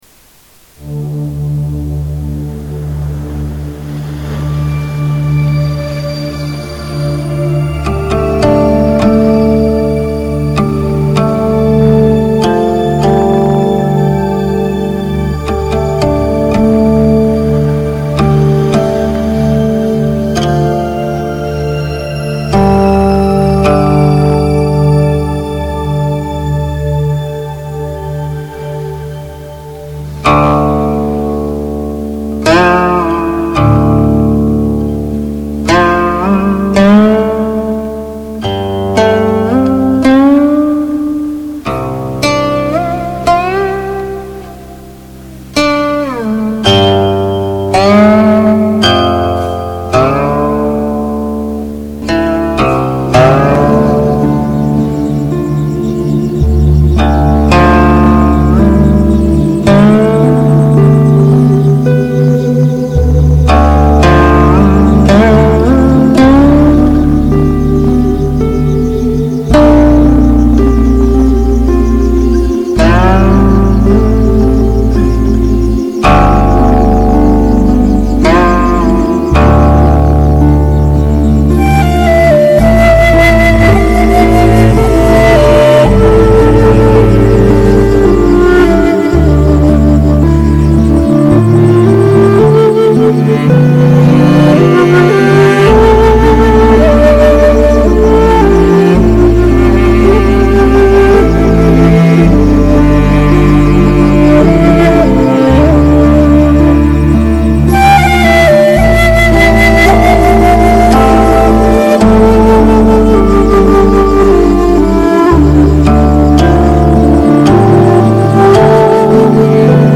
古琴，笛箫的融合仿若出水芙蓉，暗香飘飘，令人心旷神怡。
淡淡的茶香飘起，悠悠的琴音奏响，清扬的笛声划过山林的寂静，犹如暗夜空中的点点星光。